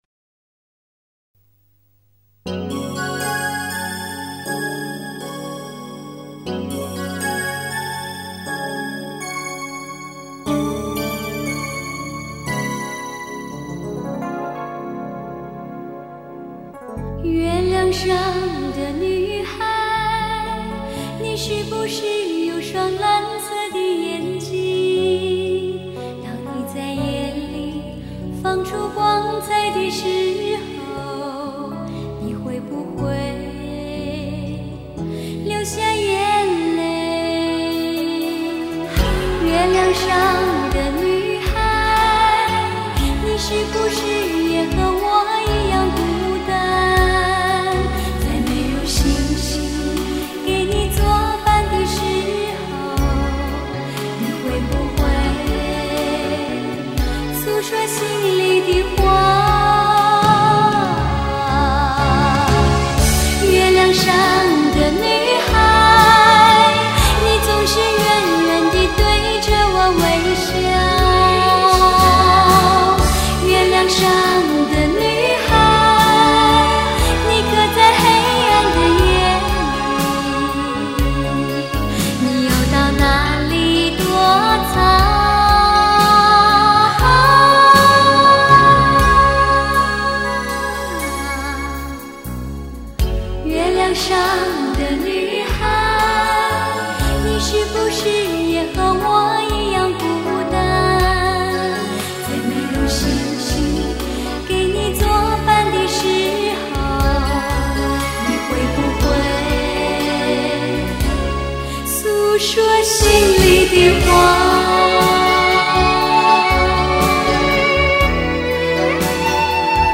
妙似仙女般的的甜美女声带着一种久违了的纯情音乐，直击心扉。